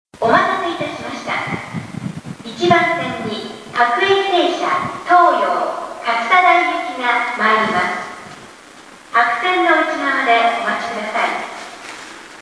駅放送